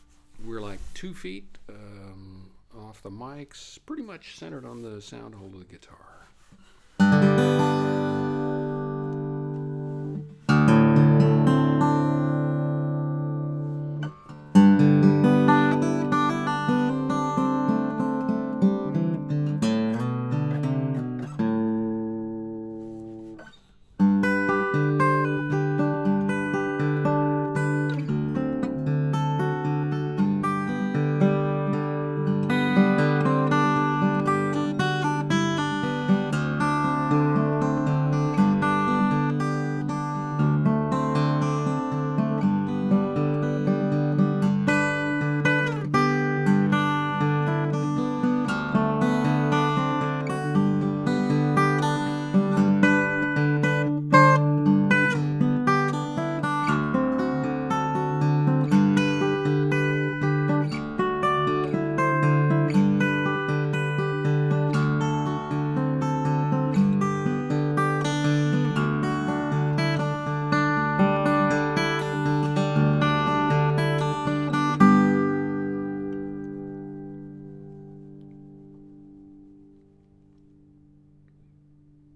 Naturally, I set the mics up as close as possible to the same position, using the same stereo arrangement.
Based on this test, it seems that a little digital EQ can make a Rode NT4 sound like a Schoeps CMC64 when recording me playing solo acoustic guitar (in this room on this day).
One of these is the Schoeps, one is the Rode without EQ, the other is the Rode with EQ.